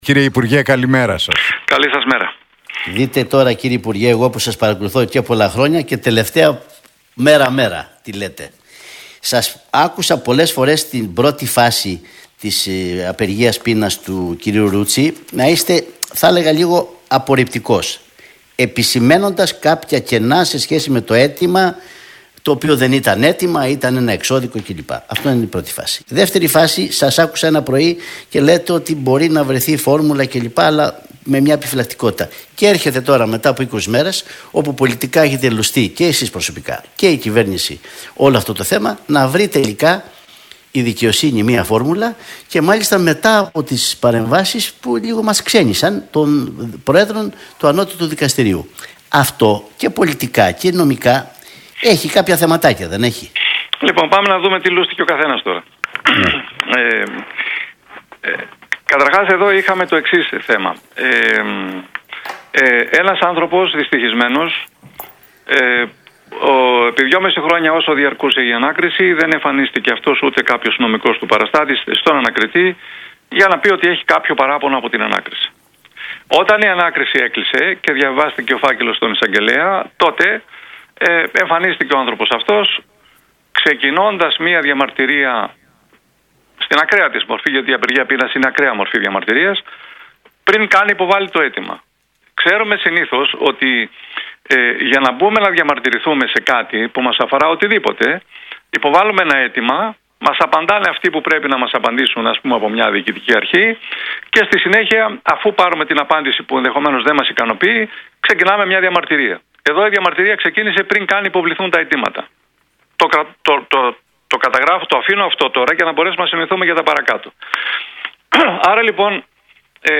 Φλωρίδης στον Realfm 97,8: Η δικαιοσύνη δεν καθοδηγείται από την πλατεία Συντάγματος – Η δίκη θα φέρει επιτέλους το φως — ΔΕΔΟΜΕΝΟ